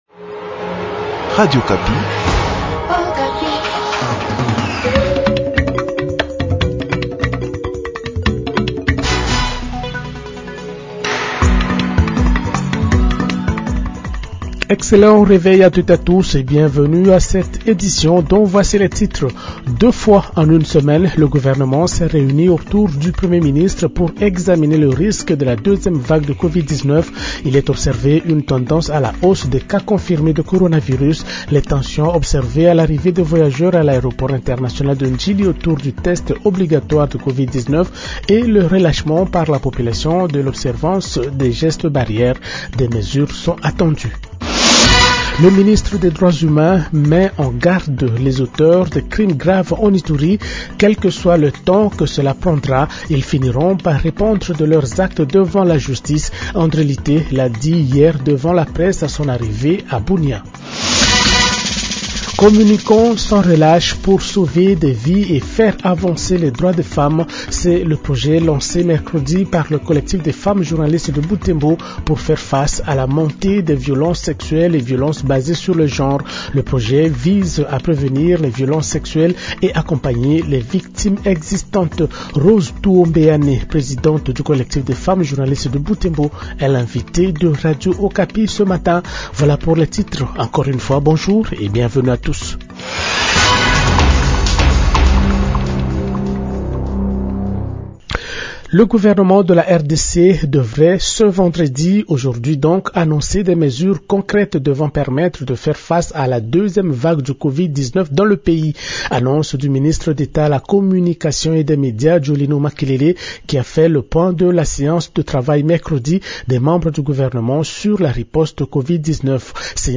Journal Francais Matin 7h00